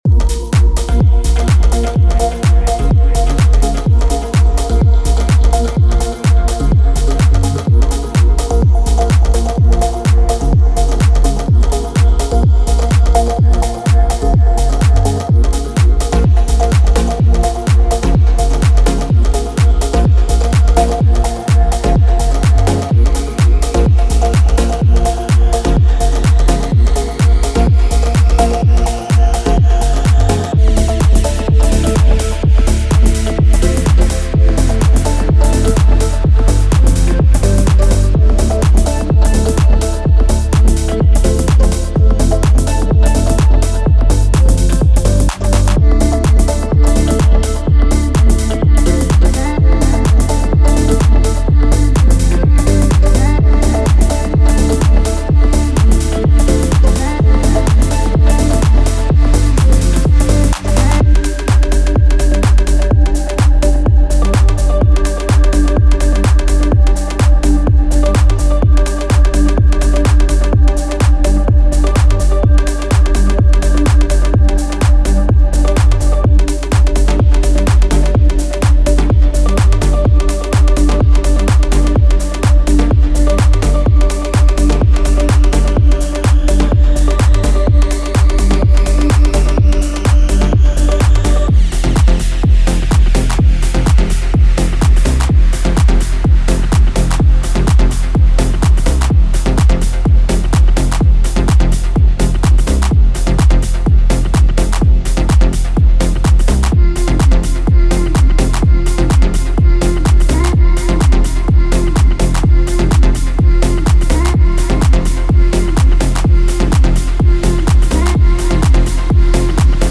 Featuring 88 high-quality loops, this collection is your ultimate toolkit for creating dynamic house music that captivates listeners and fills dance floors.
Basslines: Deep, punchy, and perfectly engineered to drive your tracks forward.
Drum Loops: From crisp hi-hats and snappy snares to thumping kicks, our drum loops provide the essential backbone for your house beats.
Synth Loops: Rich, vibrant synths that add melodic depth and harmonic complexity to your compositions.
Percussion Loops: Add rhythmic spice with our diverse array of percussive elements, perfect for adding texture and groove.
Vocal Chops: Unique and catchy vocal snippets that bring a human touch and standout hooks to your tracks.